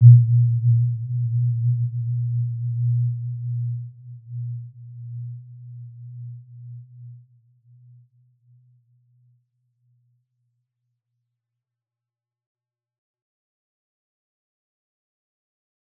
Warm-Bounce-B2-mf.wav